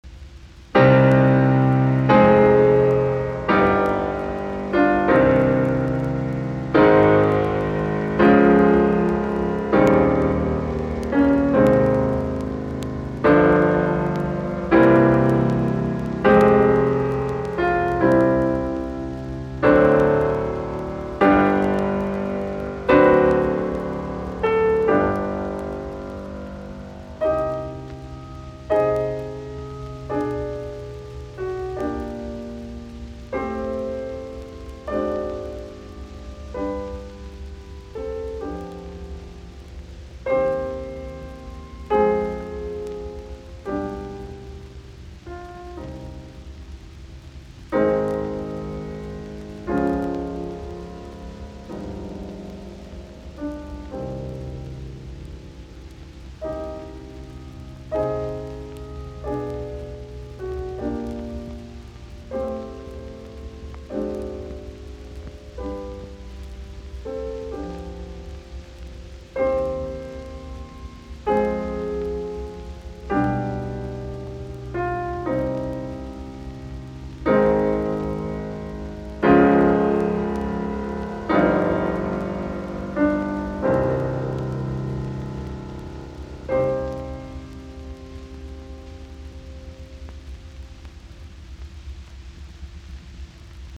in c minor, Largo